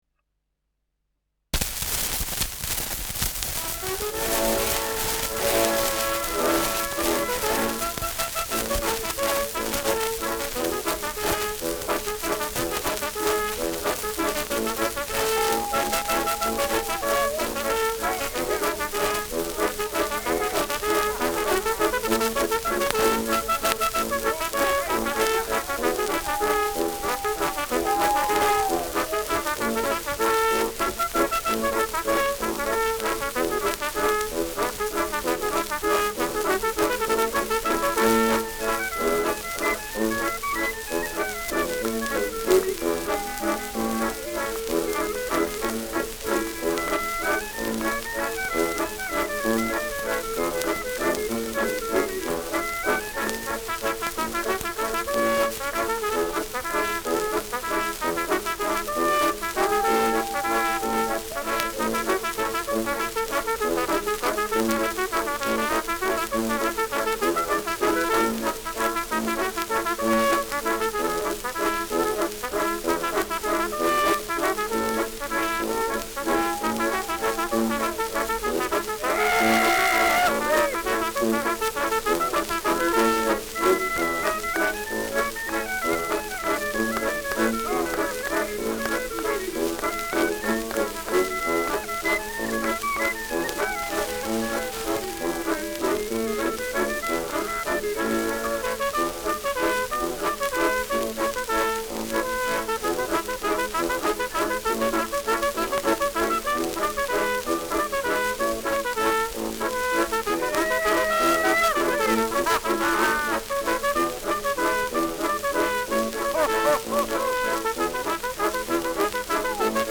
Schellackplatte
Tonrille: Kratzer Durchgehend Leicht
ausgeprägtes Rauschen : Knistern
Dachauer Bauernkapelle (Interpretation)